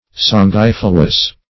Search Result for " sanguifluous" : The Collaborative International Dictionary of English v.0.48: Sanguifluous \San*guif"lu*ous\, a. [L. sanguis blood + fluere to flow.] Flowing or running with blood.
sanguifluous.mp3